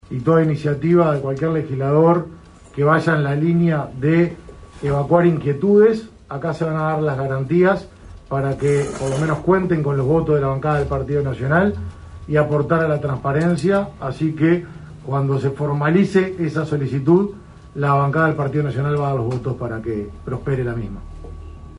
Asi lo confirmaba en conferencia de prensa el Presidente de la Cámara de Diputados Martín :